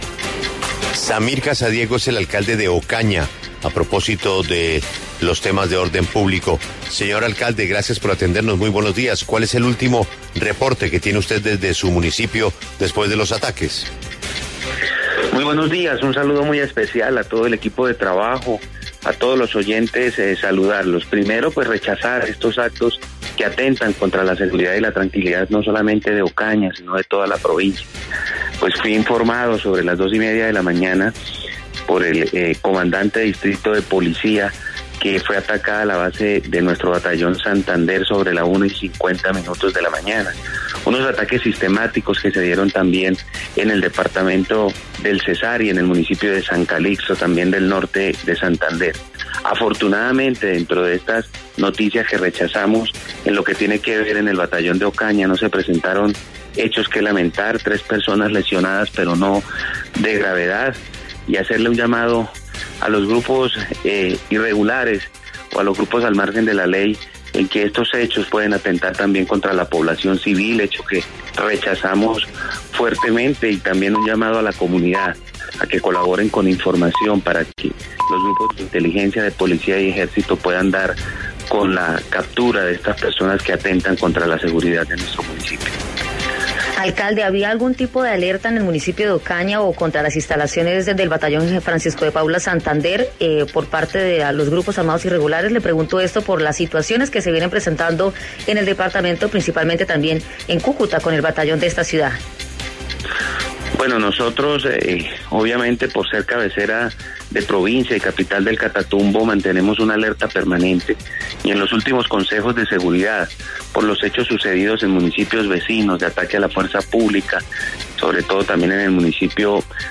En diálogo con La W el alcalde de Ocaña, Samir Sadiego, explicó las medidas a tomar frente a los explosivos con los que atacaron las instalaciones del batallón Santander en Ocaña.